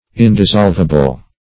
Search Result for " indissolvable" : The Collaborative International Dictionary of English v.0.48: Indissolvable \In`dis*solv"a*ble\, a. [Pref. in- not + dissolvable.
indissolvable.mp3